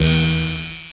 pickup minecraft sound
pickup-minecraft-sound-4656at7z.wav